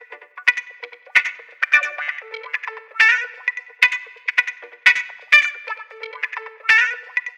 Electric Guitar 02.wav